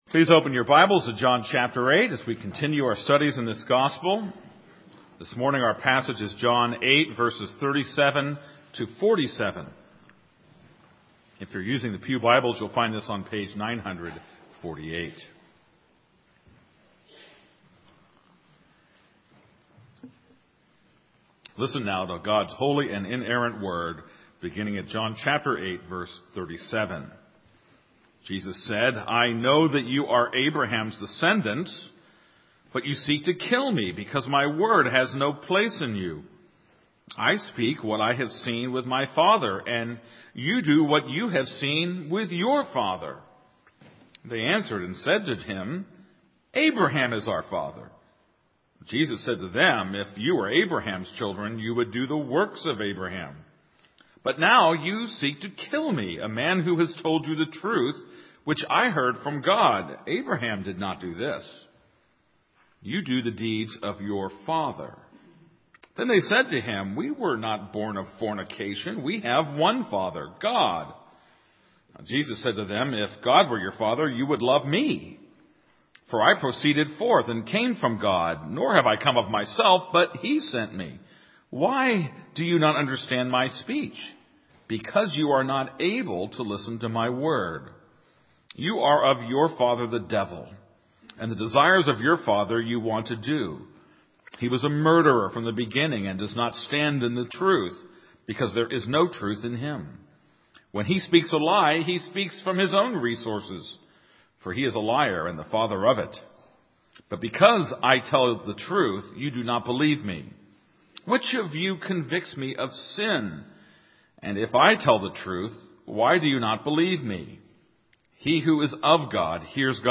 This is a sermon on John 8:37-47.